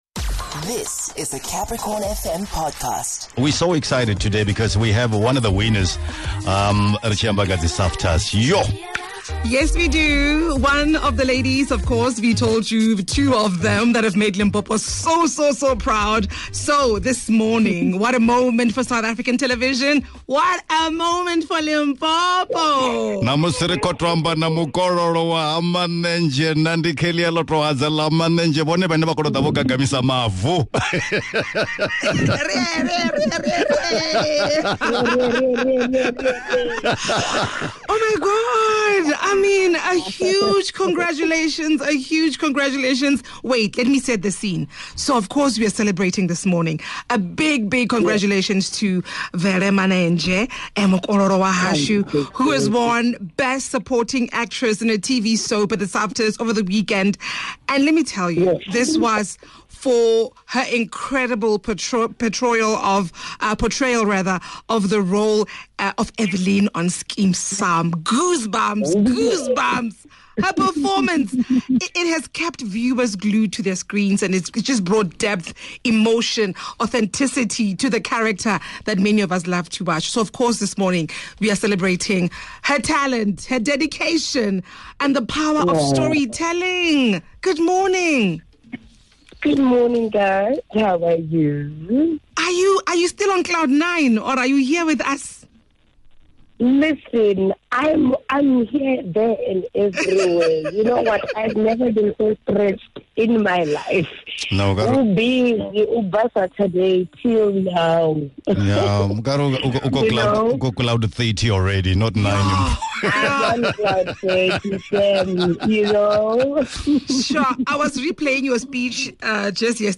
18 Mar Special Interview